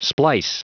Prononciation du mot splice en anglais (fichier audio)
Prononciation du mot : splice